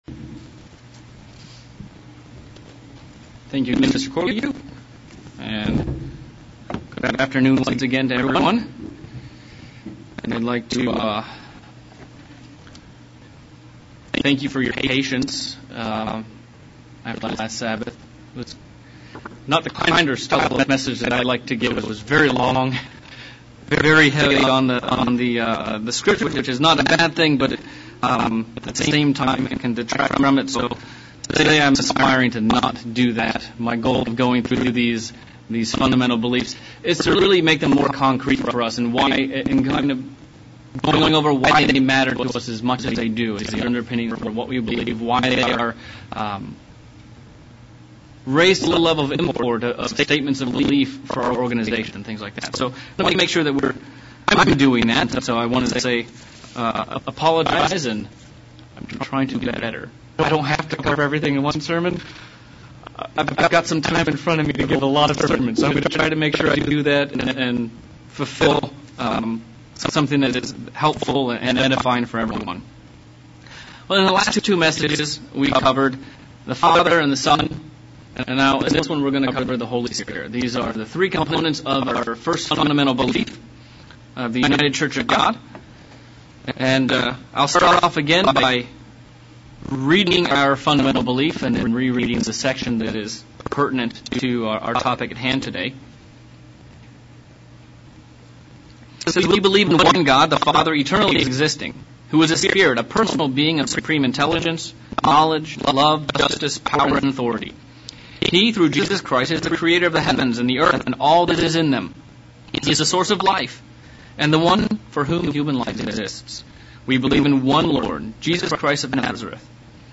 Fundamentals of Belief sermon looking at the Power of God through the Holy Spirit.